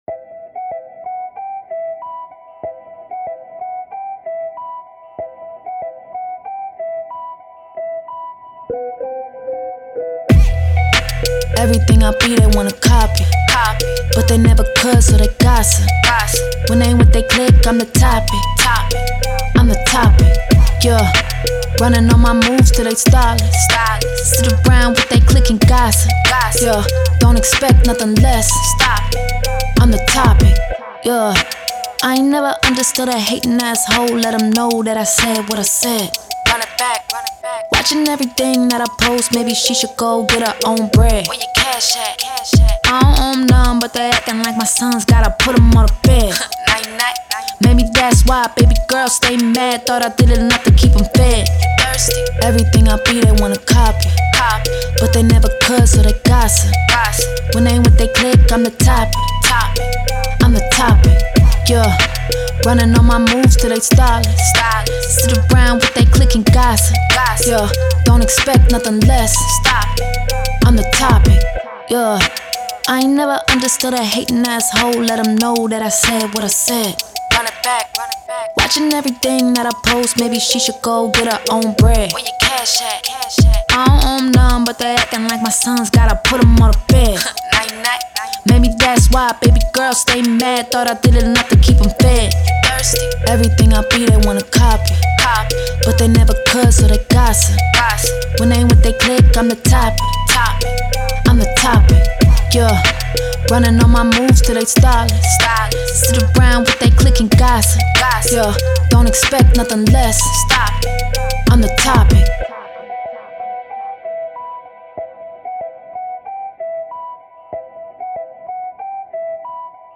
Hip Hop
E Maj